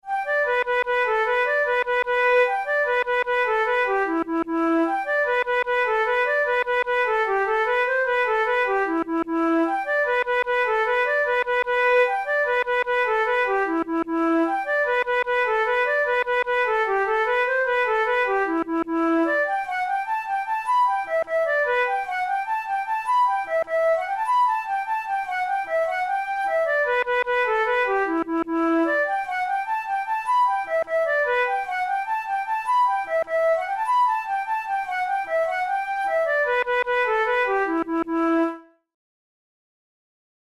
InstrumentationFlute solo
KeyG major
Time signature6/8
Tempo100 BPM
Jigs, Traditional/Folk
Traditional Scottish/Irish jig